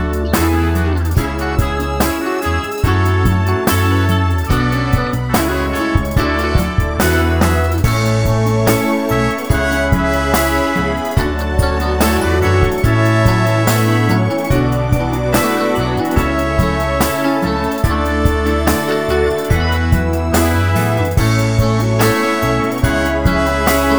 no Backing Vocals Pop (1970s) 4:04 Buy £1.50